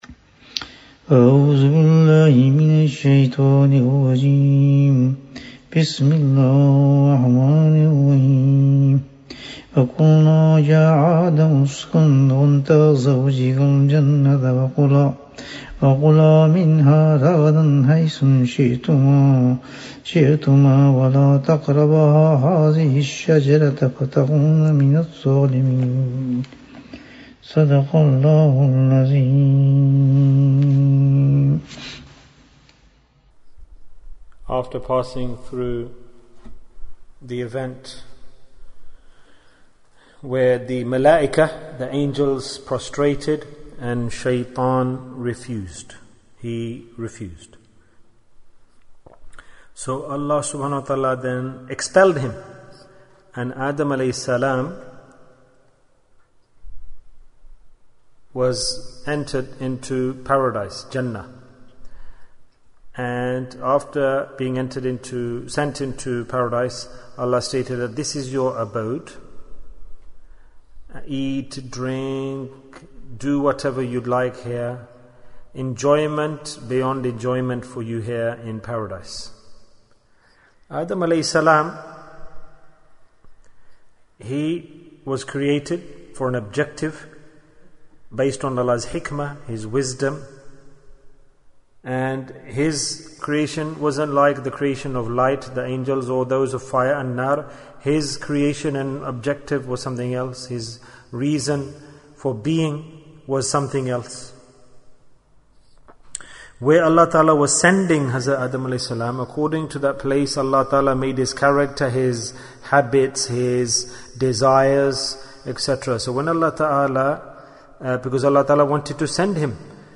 What Mehr Did Adam (as) Give to Hawa (as)? - Dars 35 Bayan, 40 minutes18th July, 2020